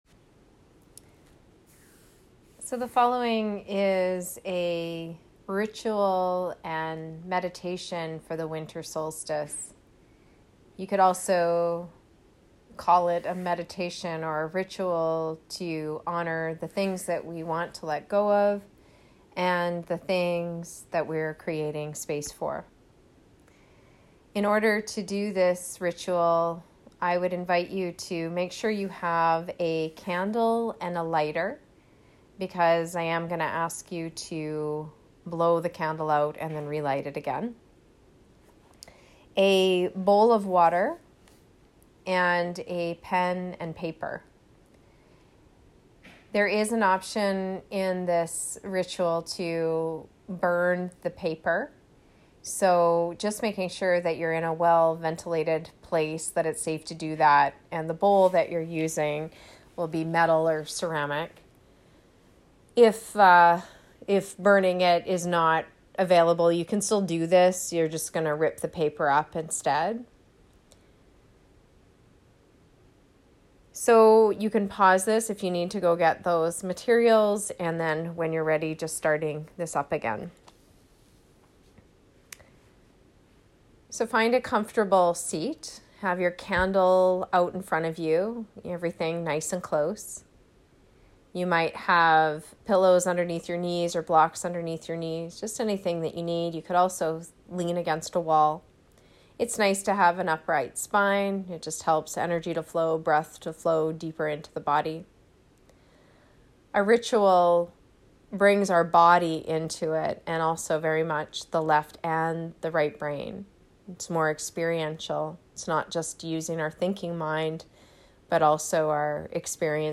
Winter Solstice Ritual/Meditation - Letting Go & Inviting In